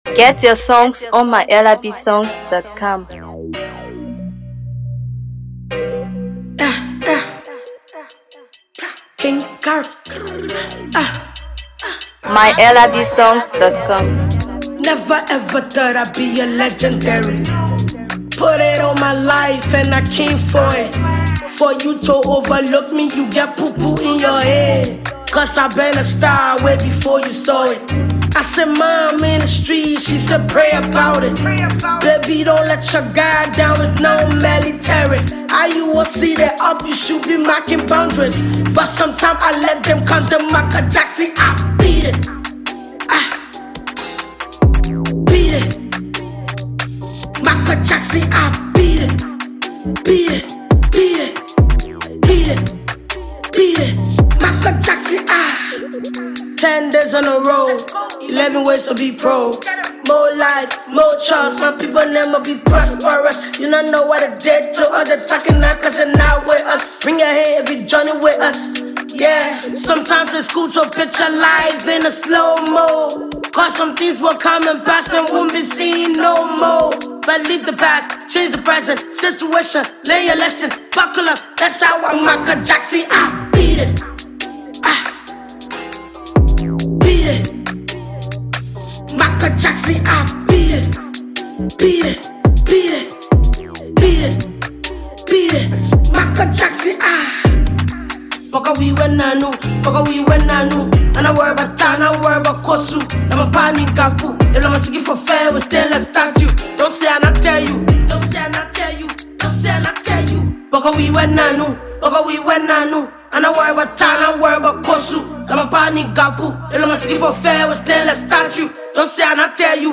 Known for her unique blend of Afrobeat, Dancehall, and Hipco